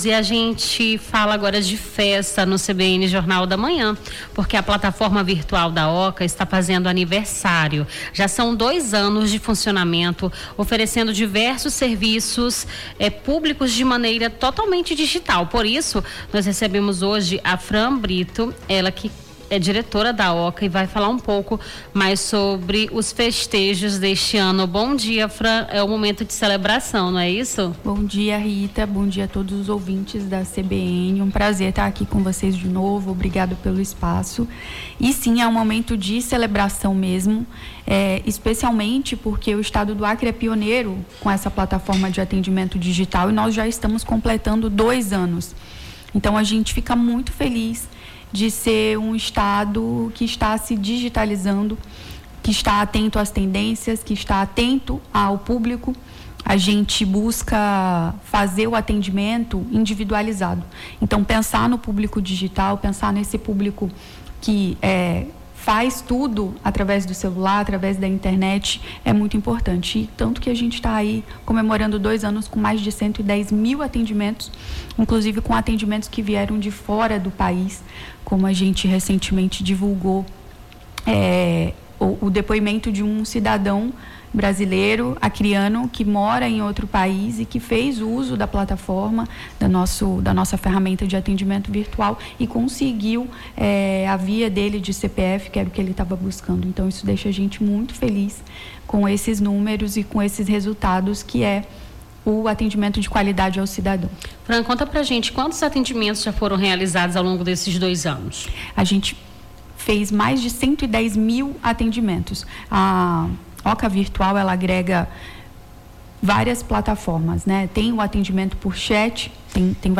Nome do Artista - CENSURA- ENTREVISTA ANIVESÁRIO OCA (08-04-25).mp3